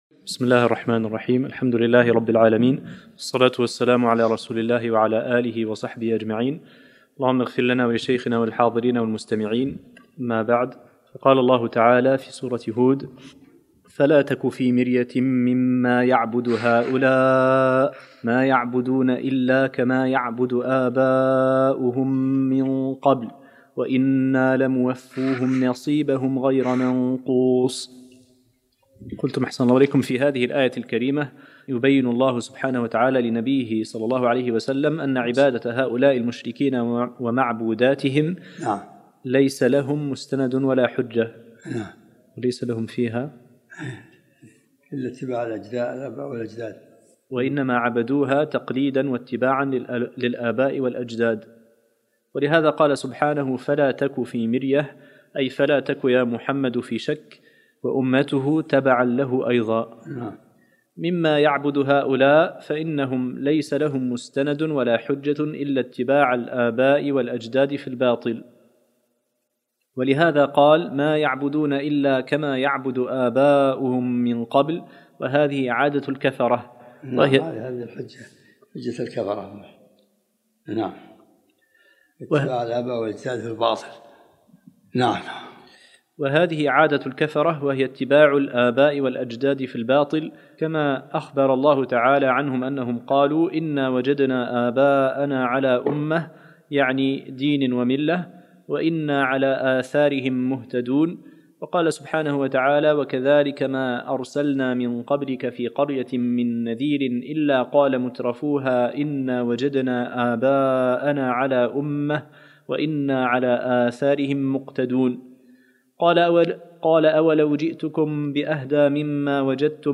الدرس السادس من سورة هود